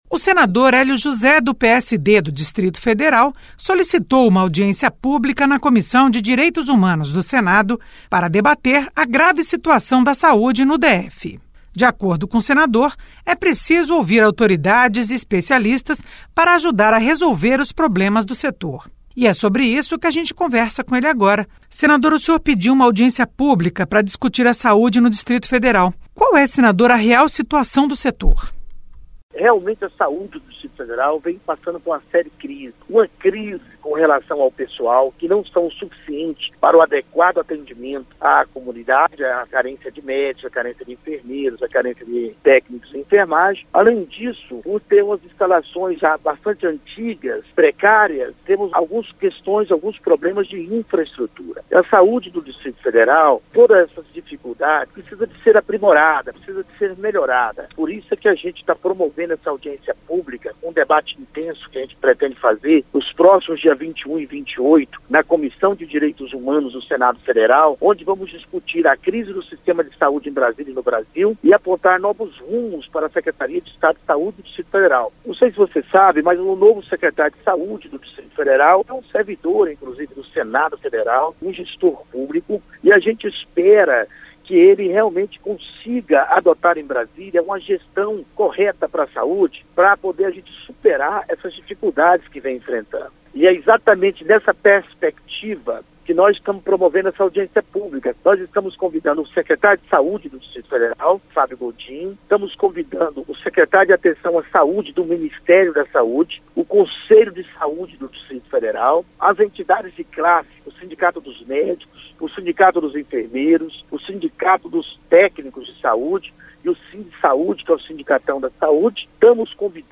Entrevistas regionais, notícias e informações sobre o Senado Federal